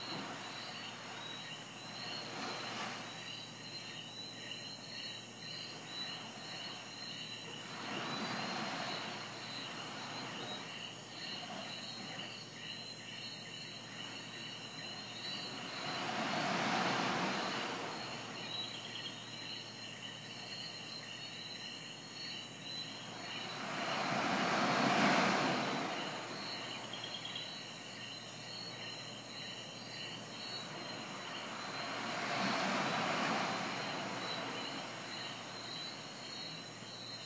One hour before high tide.